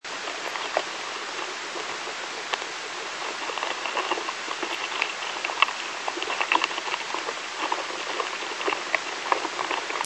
As worms move through soil, their skin rubs against grains of dirt. That makes rustling or crackling sounds.
This is what earthworms sound like in a microphone.